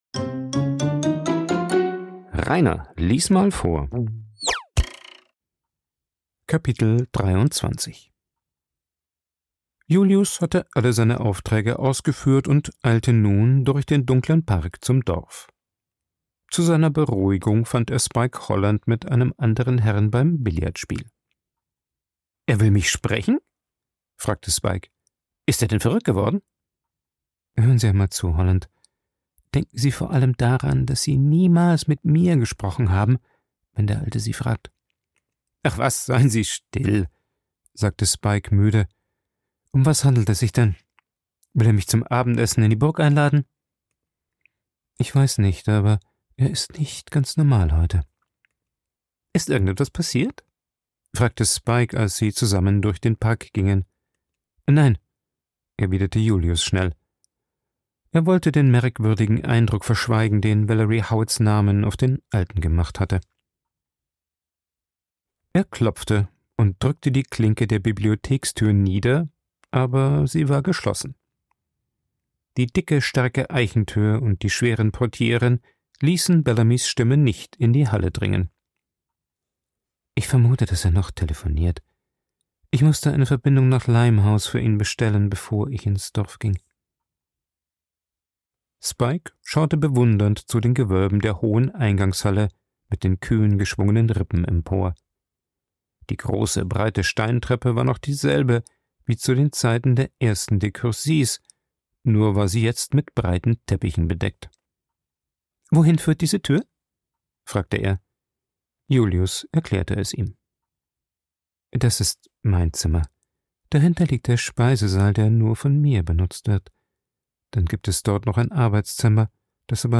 Ich lese Bücher vor, vorwiegend Klassiker der Weltliteratur, weil ich Spass am Vorlesen habe. Jeden 2. oder 3. Tag wird ein Kapitel eines Buches veröffentlicht, so dass mit der Zeit komplette Hörbücher entstehen.